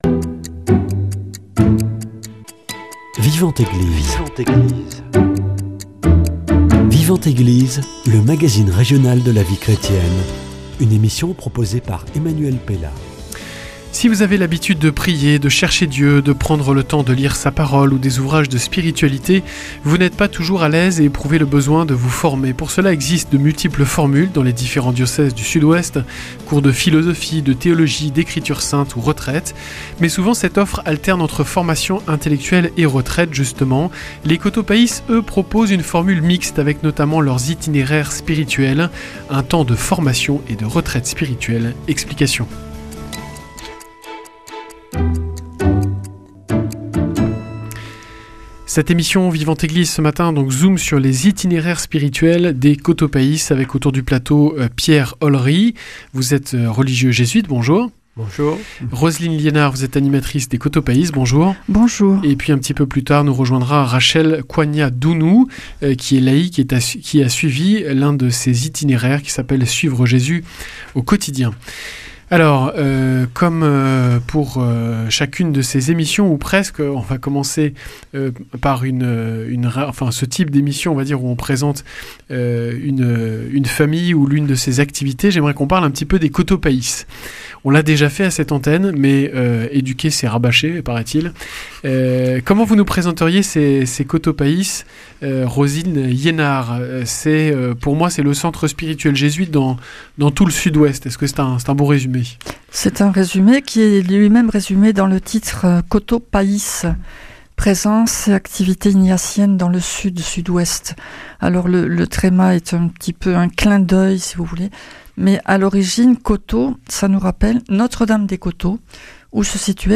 Rencontre.